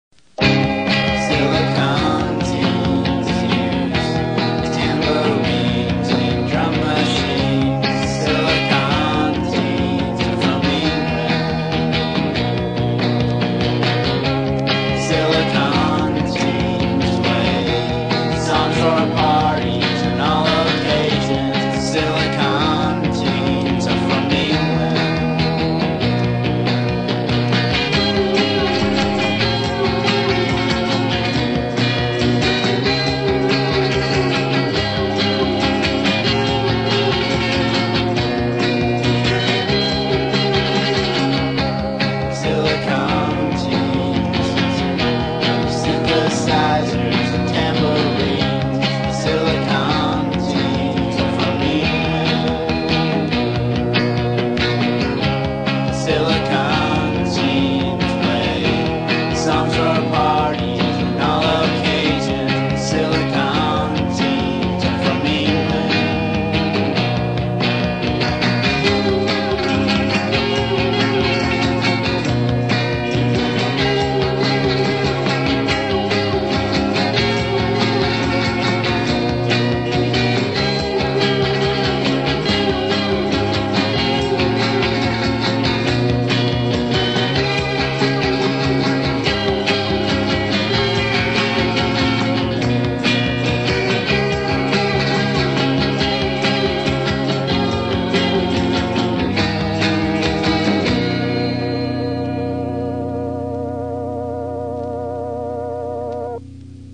They had a great 80’s synthpop sound
a stripped down, guitar driven version